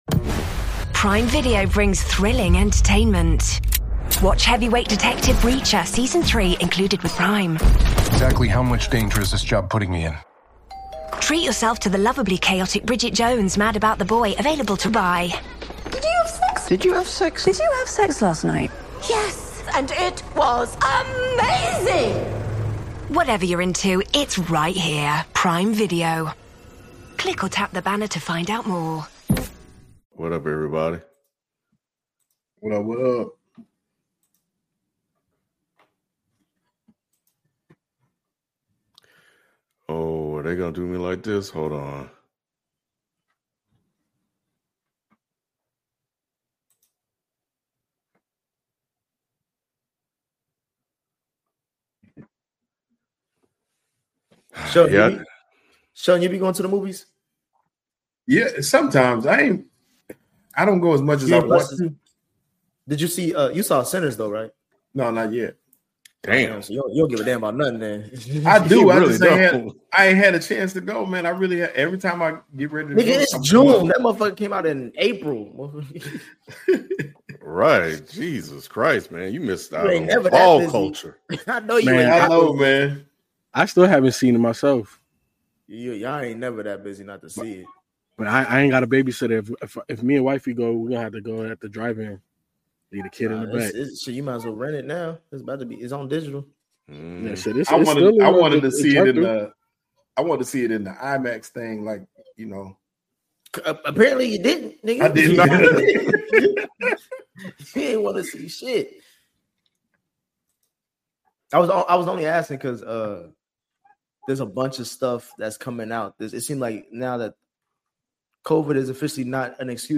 We hung out and watched game one of the NBA Finals last night between the Indiana Pacers and Oklahoma City Thunder. We react to the shot in real time and debate what happened to OKC down the stretch.